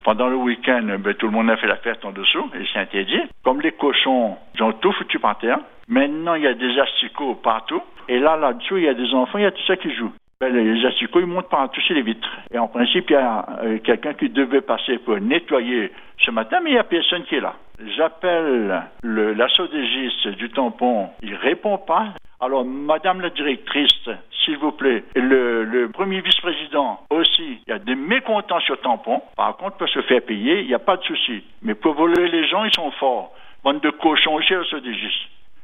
À la résidence Jean Payet, dans le quartier de La Chatoire au Tampon, un habitant a poussé un véritable coup de gueule sur notre antenne.